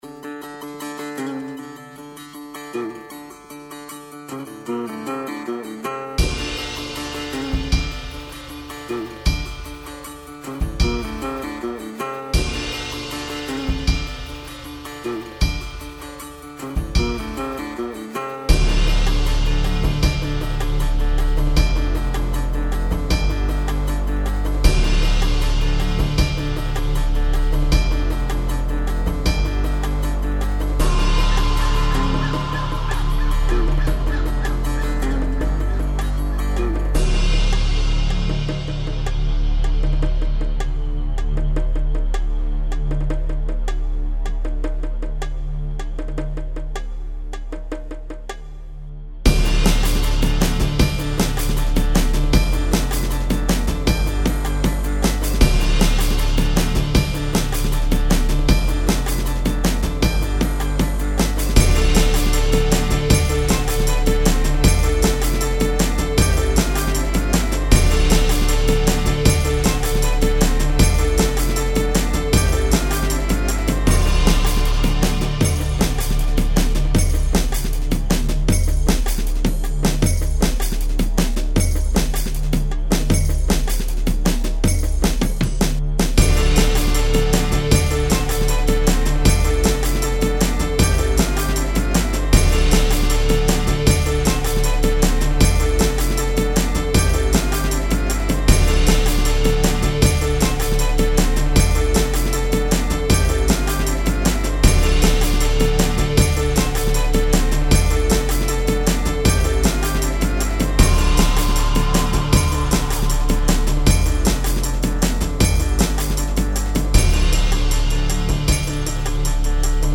Genre: Drum & Bass / Ethnic